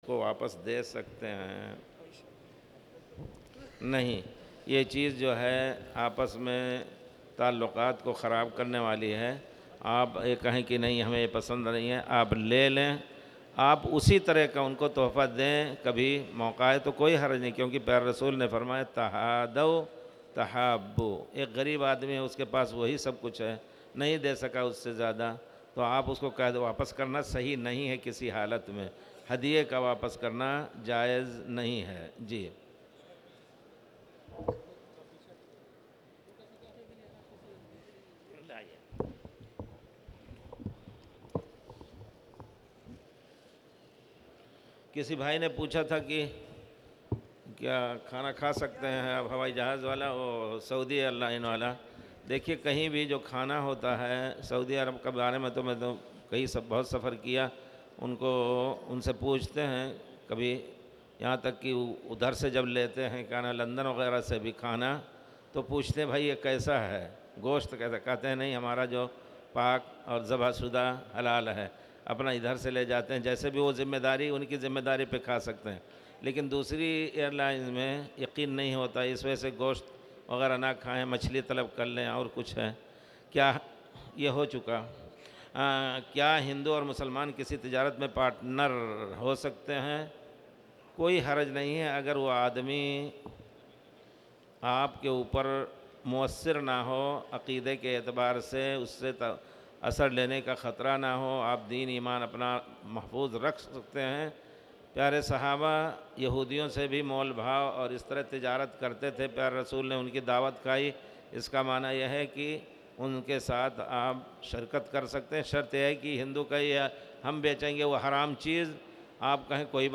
تاريخ النشر ١٩ ذو الحجة ١٤٣٨ هـ المكان: المسجد الحرام الشيخ